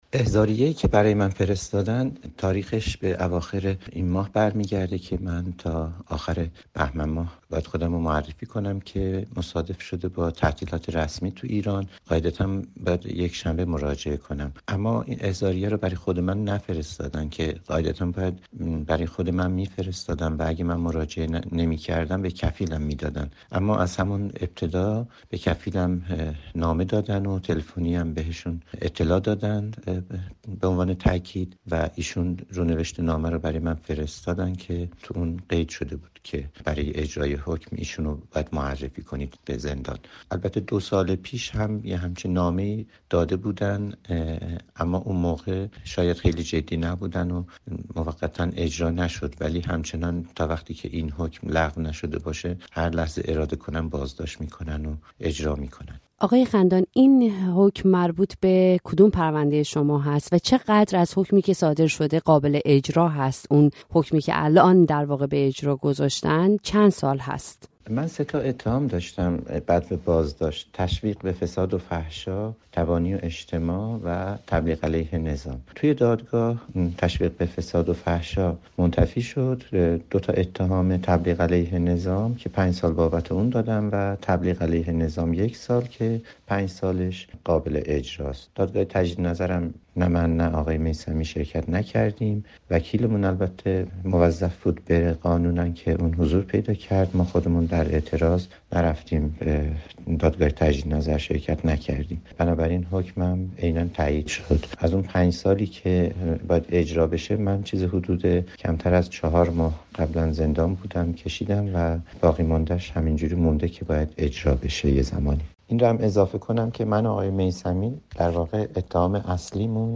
در گفتگو با رادیوفردا